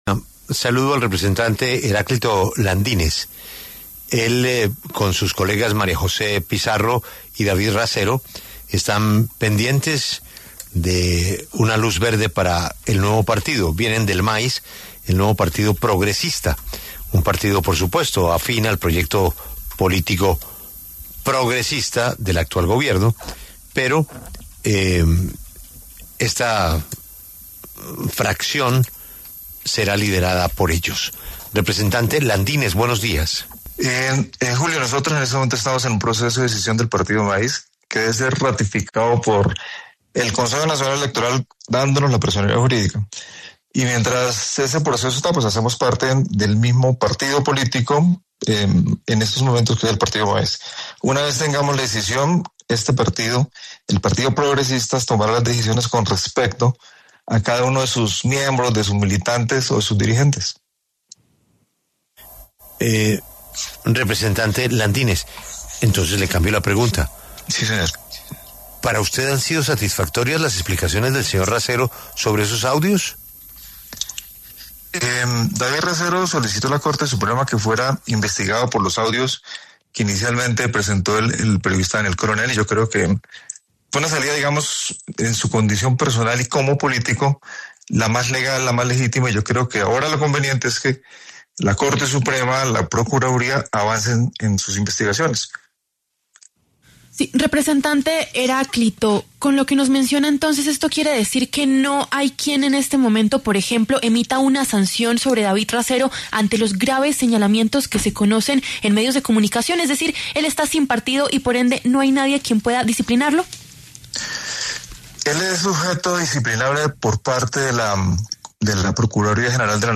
El representante Heráclito Landinez, del Pacto Histórico habló para La W y aseguró que ningún partido político puede sancionar a David Racero.